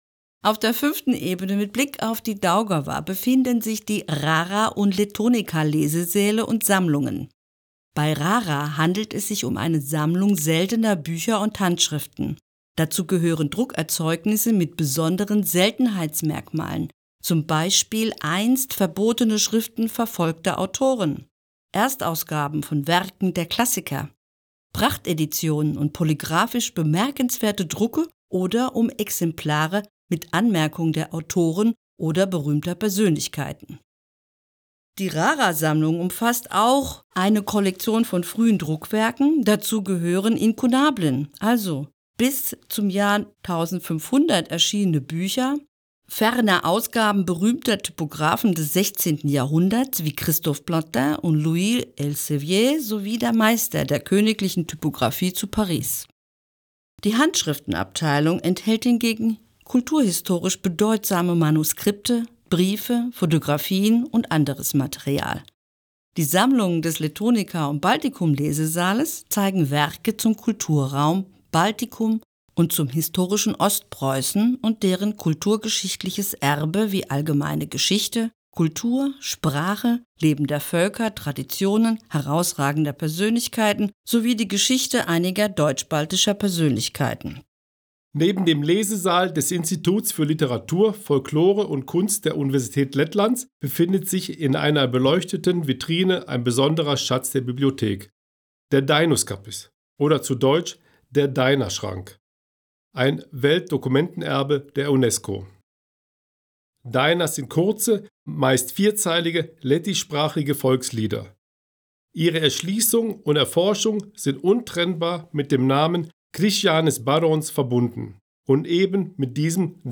balss aktieris
Tūrisma gidi